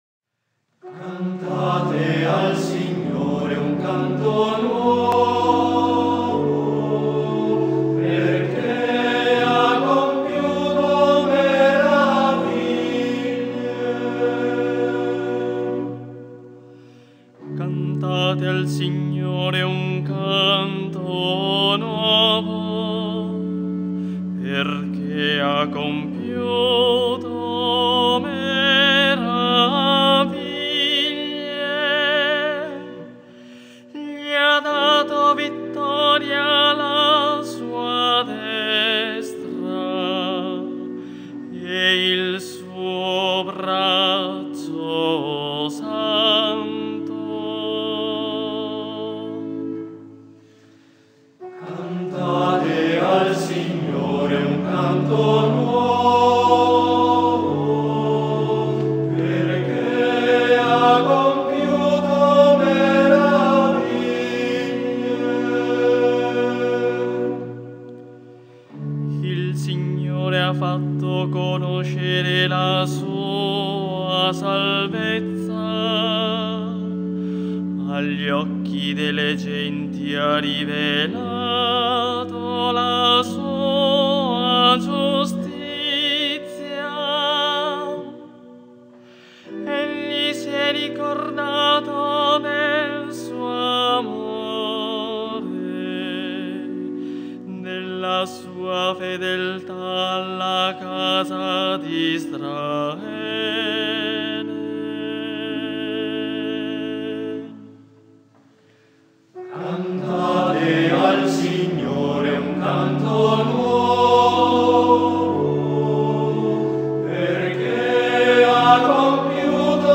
Salmo responsoriale
Immacolata-Concezione-della-B.V.-Maria-Salmo-Responsoriale.mp3